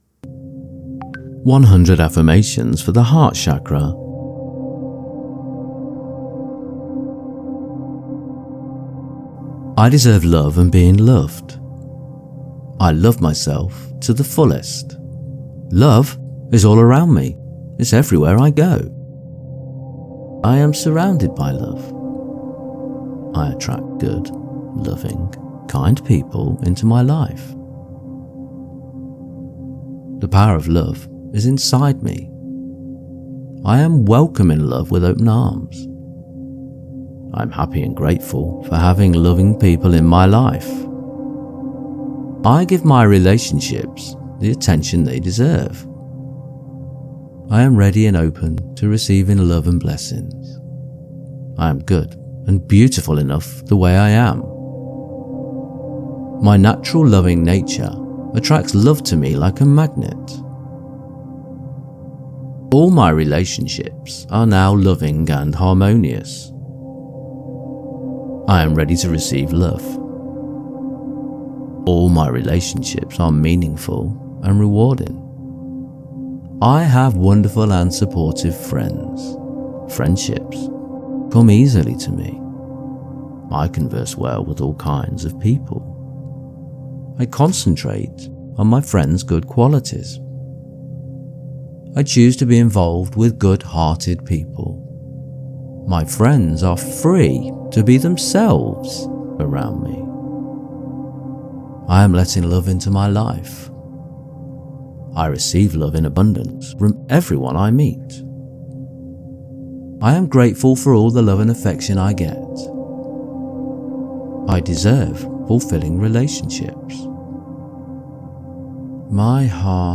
Heart Chakra Affirmations for Joy and Happiness
Immerse yourself in the powerful 528 Hz frequency, often called the “Miracle” tone, known for its ability to promote DNA repair, reduce stress, and activate the heart chakra. This frequency is ideal for those seeking deep meditation, enhanced creativity, and emotional healing.
528-affirmations.mp3